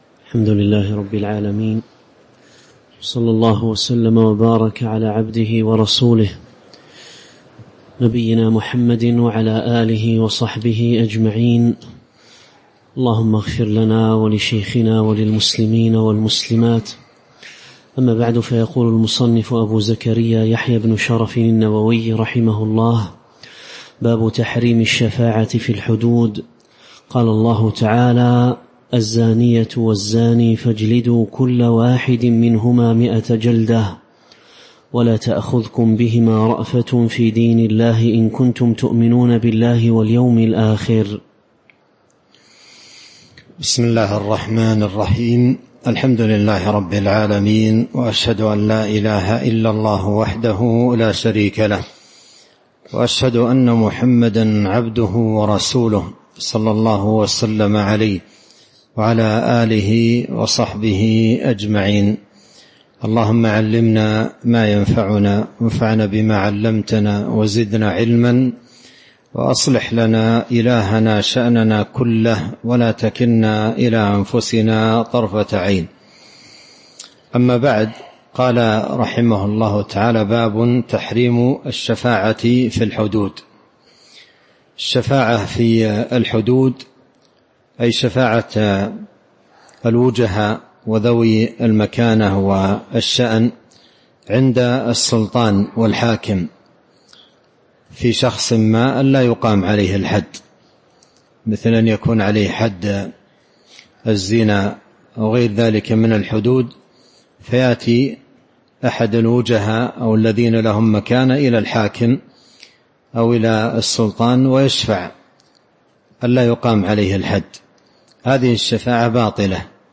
تاريخ النشر ١٩ ذو القعدة ١٤٤٥ هـ المكان: المسجد النبوي الشيخ